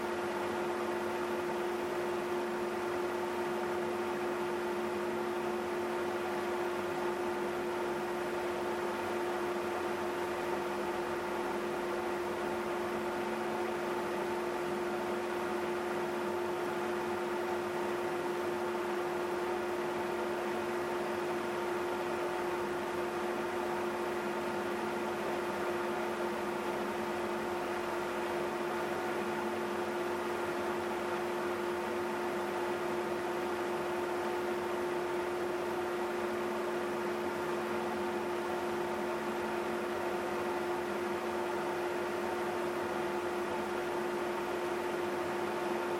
The highest spike is at 400 Hz, but I also noticed a notable one at 80 Hz.
I have recorded the signals shown above, but please keep in mind that I’ve enabled Automatic Gain Control (AGC) to do so to make it easier for you to reproduce them.
50% Fan Speed